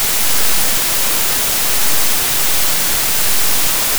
I spent the last few hours sonifying the Barkhausen effect—the specific way magnetic domains snap into place under stress. It’s not smooth. It’s jagged. It’s loud.
Listen to that crackle.
Every snap is a choice not to be efficient.